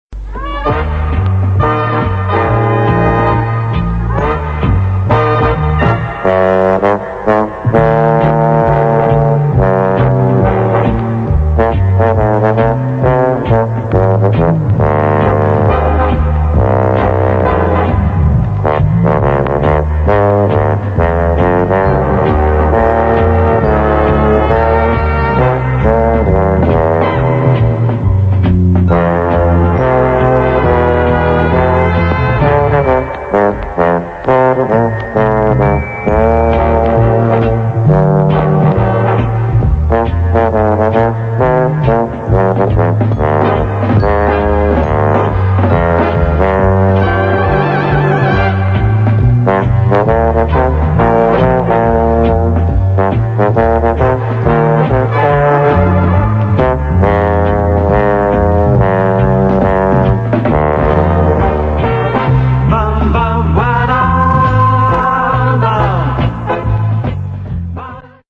Пожалуйста помогите опознать оркестровую пьесу
orchestra.mp3